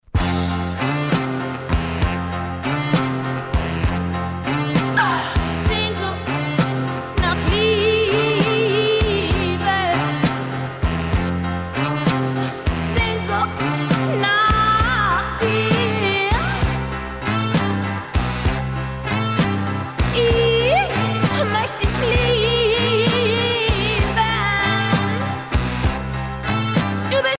danse : slow fox
Pièce musicale éditée